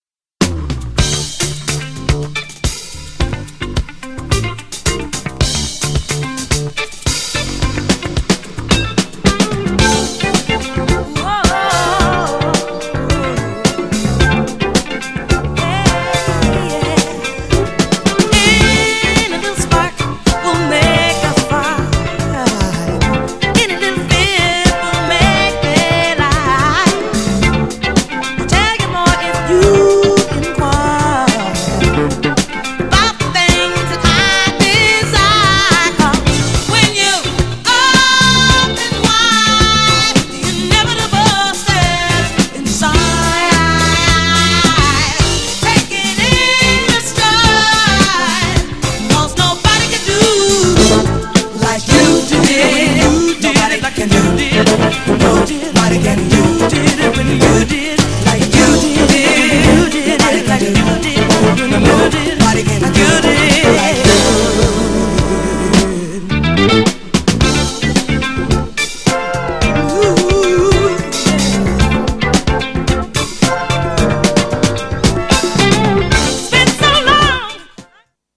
GENRE Dance Classic
BPM 111〜115BPM
熱いボーカル